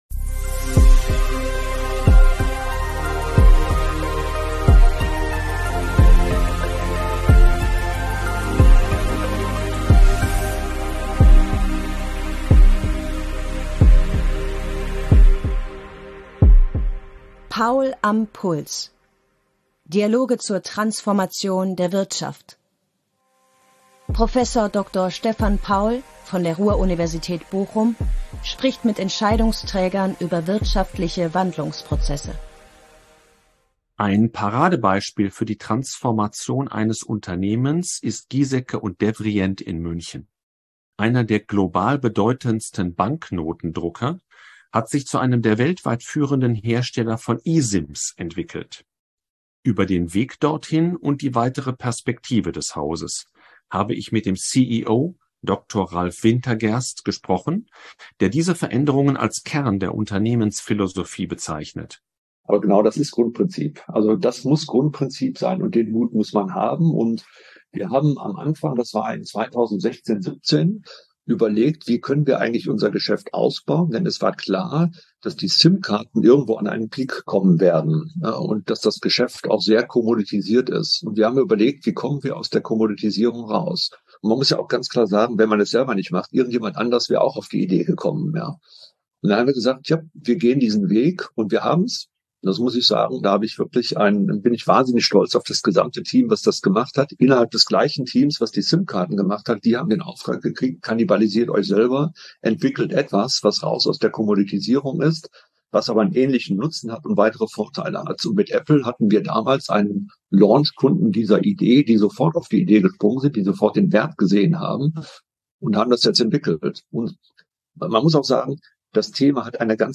Dialoge zur Transformation der Wirtschaft Puls messen.